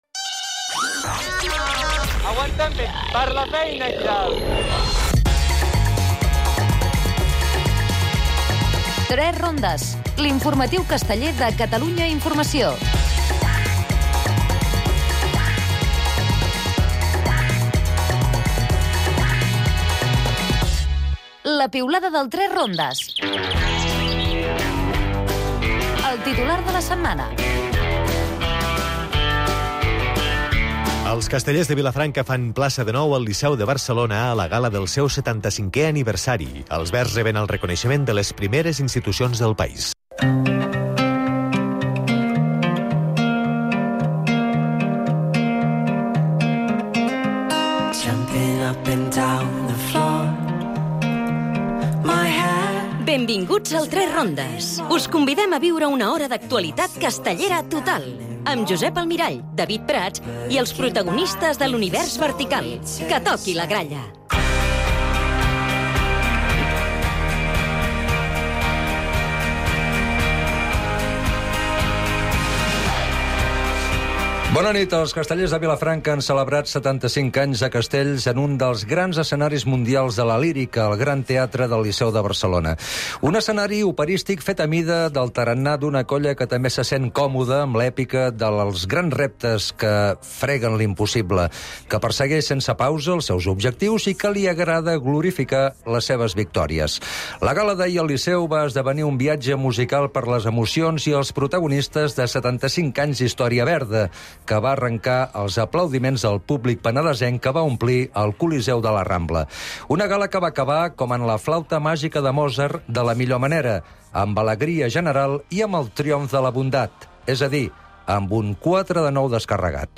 Al 3 Rondes hem estat a les actuacions de la Nova Atenes de Terrassa i Sant Miquel a Lleida. Acte del 75 aniversari dels Castellers de Vilafranca al Gran Teatre del Liceu. Prvia de la diada del Mercadal de Reus.